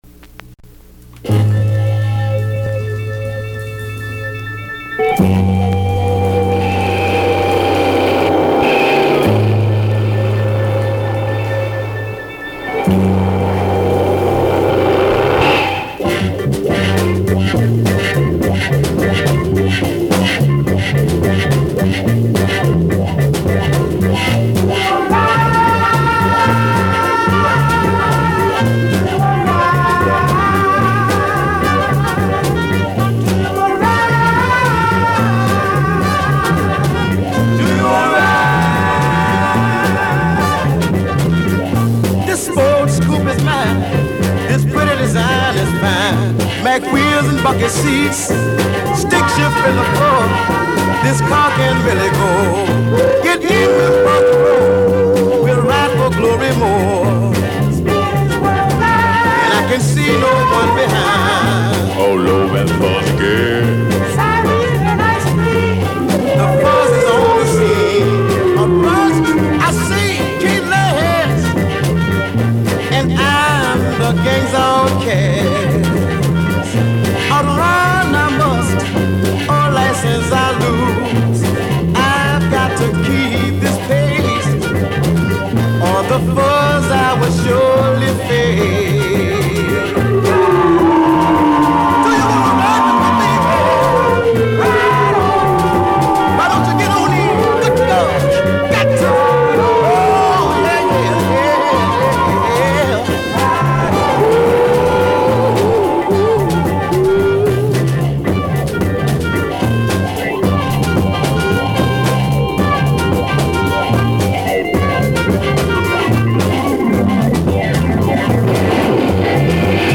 wah wah guitar, organ & trumpet